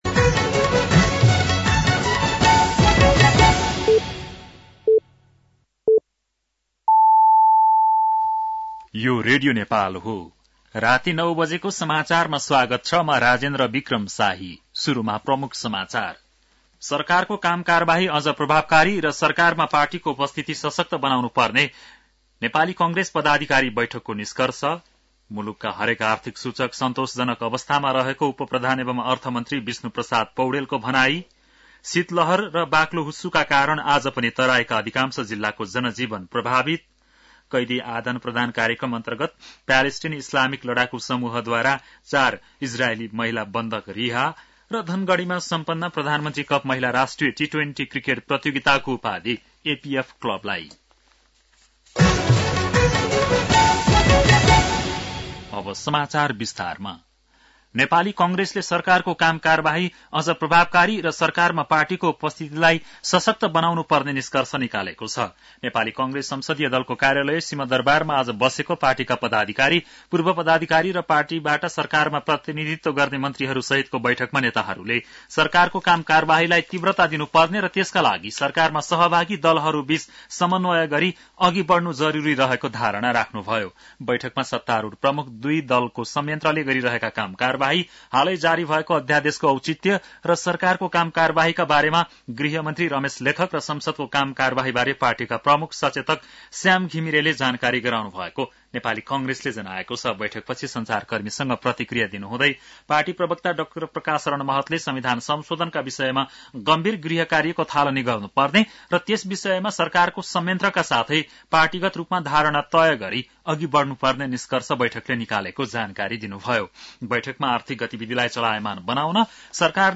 बेलुकी ९ बजेको नेपाली समाचार : १३ माघ , २०८१